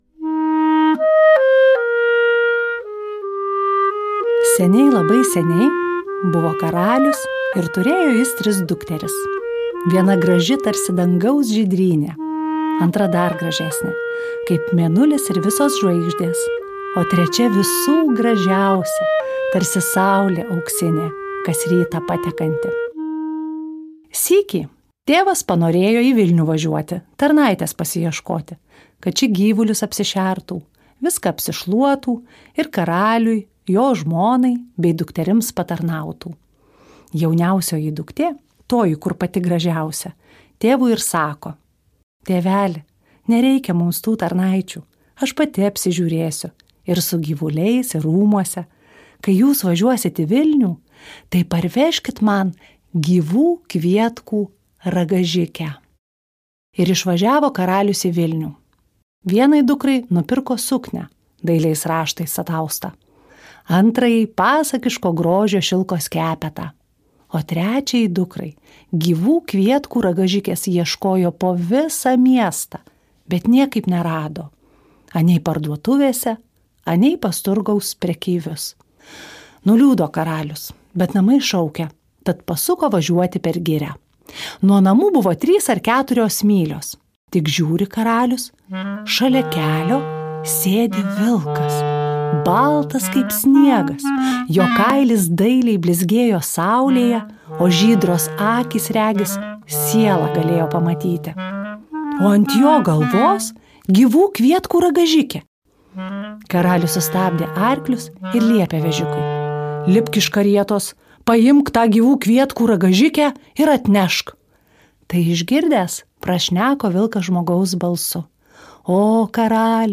Tinklalaidė įrašyta Lietuvos nacionalinės Martyno Mažvydo bibliotekos garso įrašų studijoje